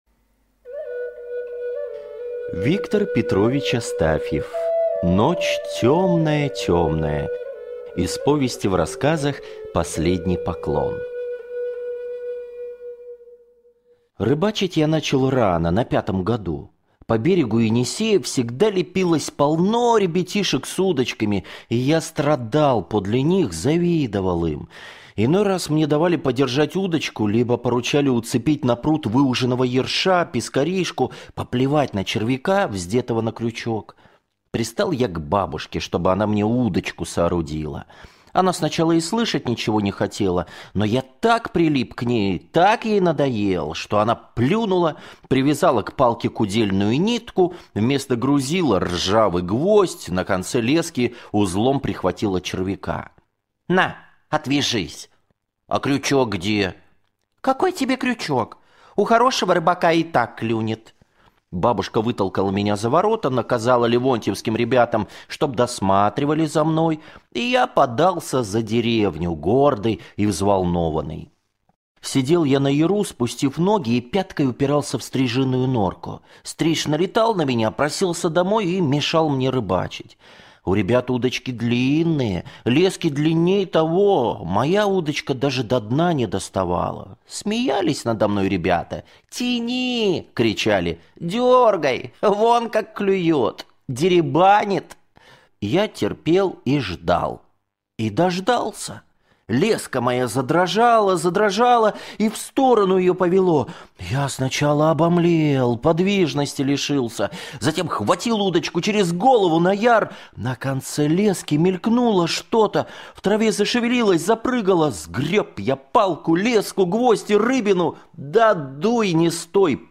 Ночь темная-темная - аудио рассказ Астафьева - слушать